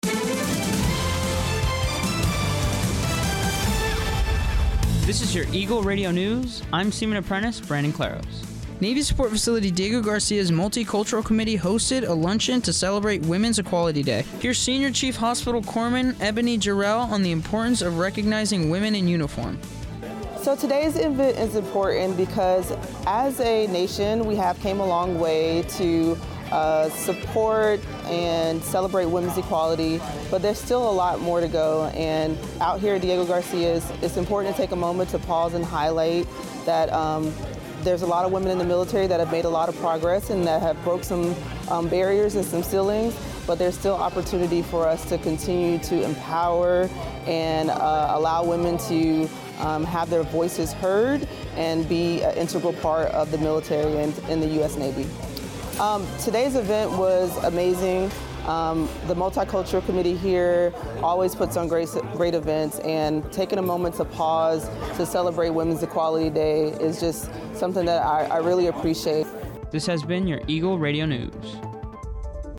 Eagle Radio News is the American Forces Network Diego Garcia’s official radio newscast.